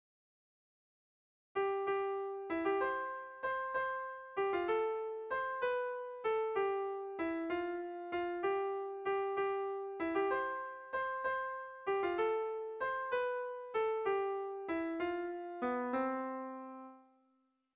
Erromantzea
A1A2